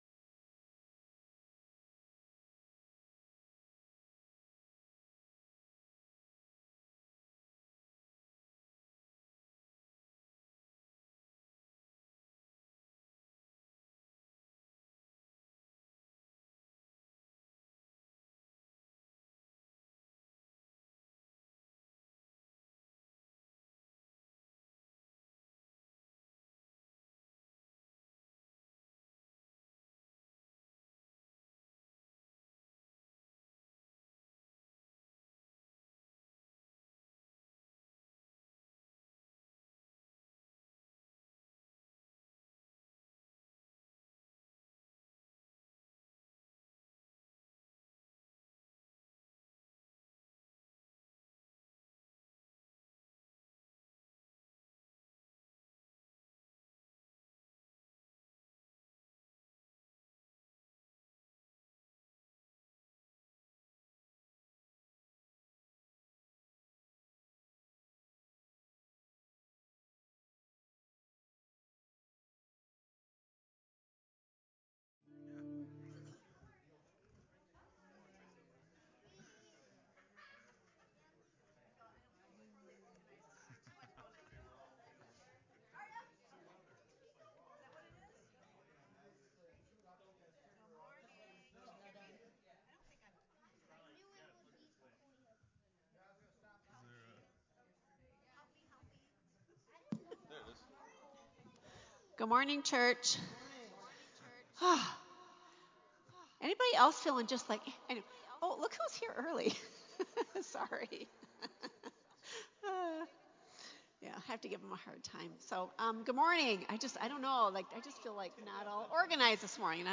Another excellent display of the musical talent God has given our worship team.